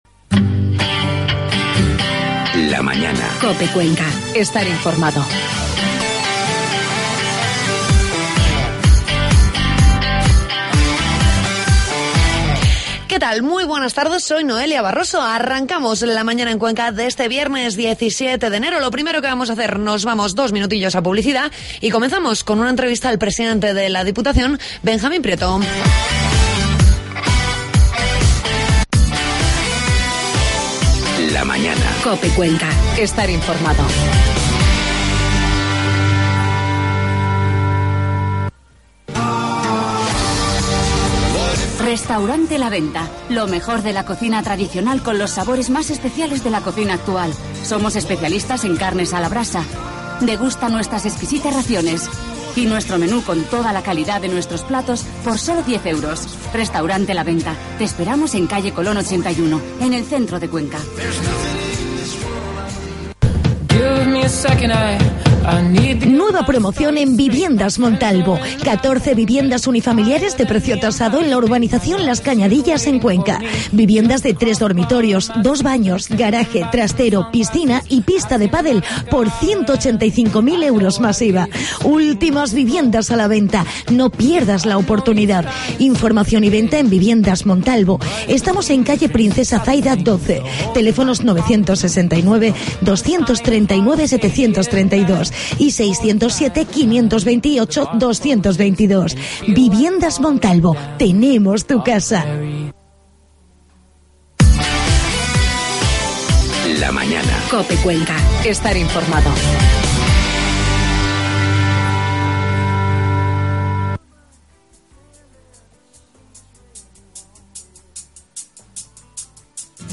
Entrevistamos al presidente de la Diputación, Benjamín Prieto y damos paso a la tertulia de todos los viernes.